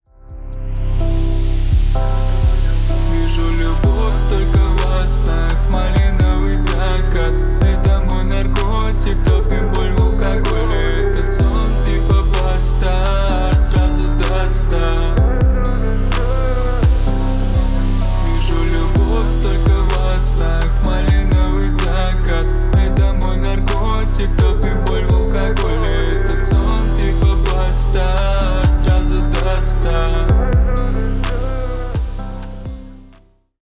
Поп Музыка
тихие # спокойные